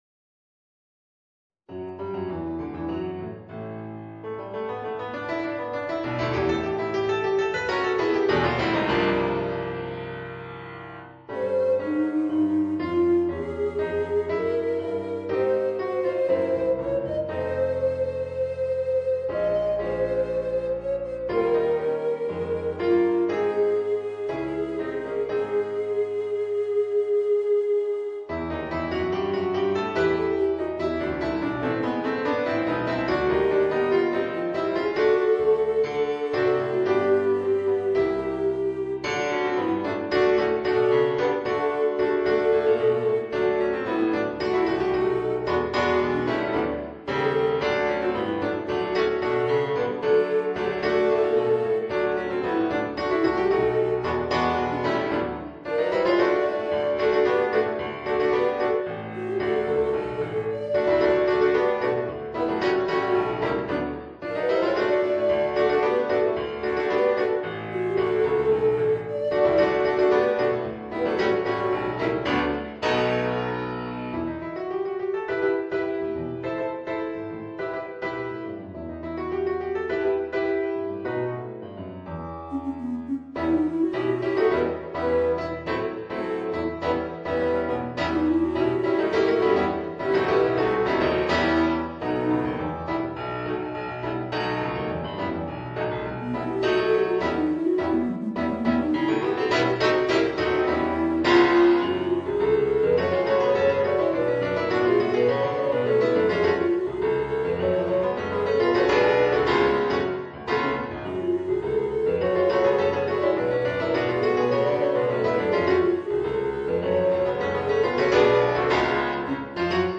Voicing: Bass Recorder and Piano